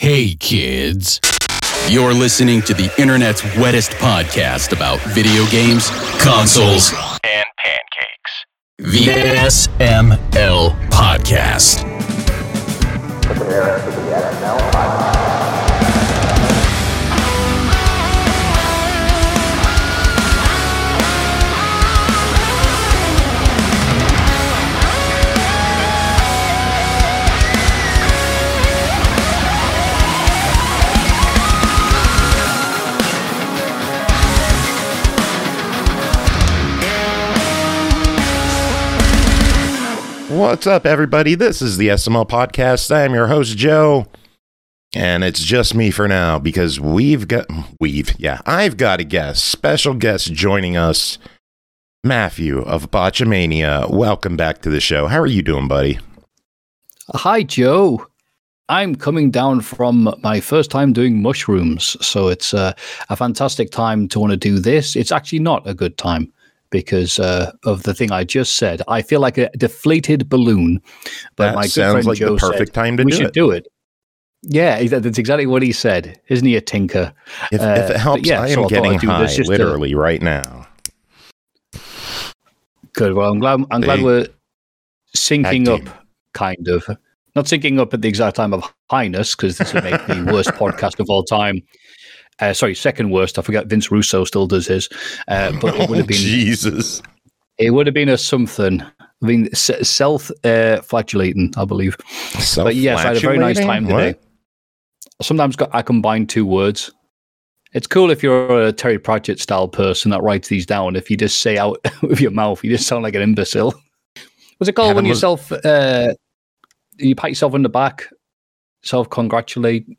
Surprise interview?